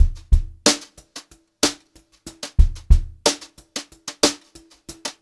92HRBEAT2 -R.wav